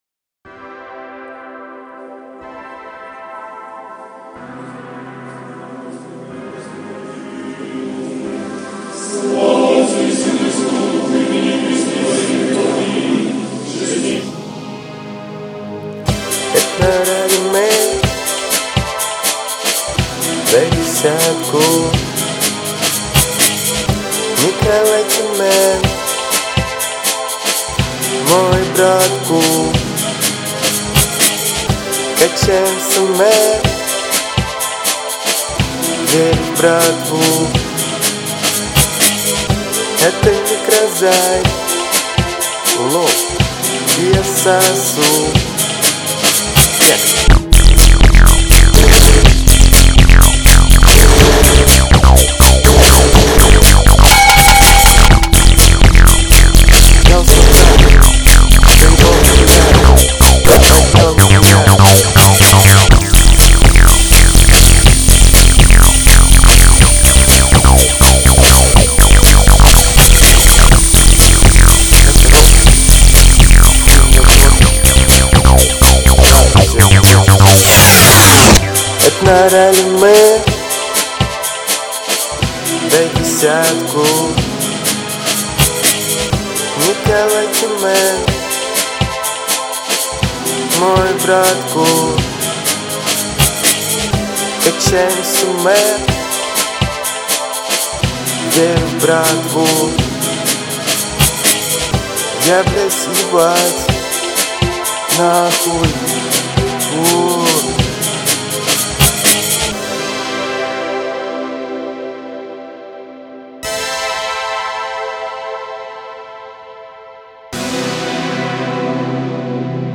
Вокал не оч, но как вам идея?